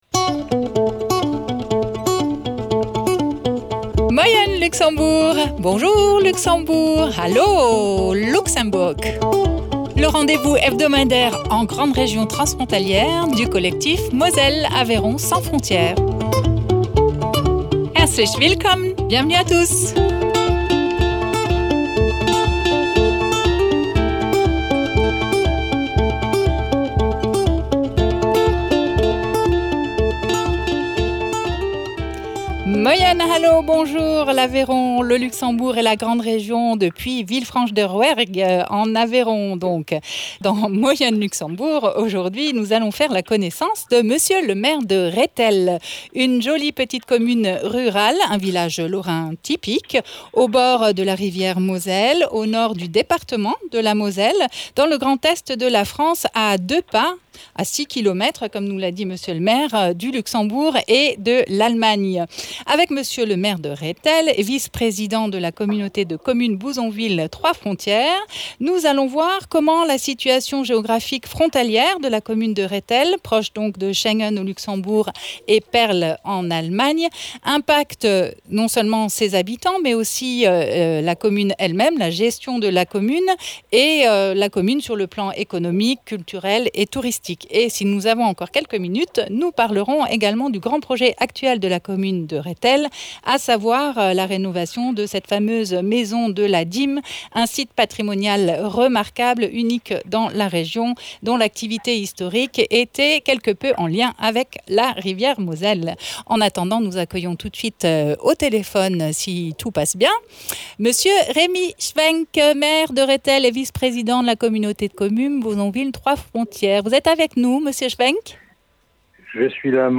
Invité(s) : Remi Schwenck, maire de Rettel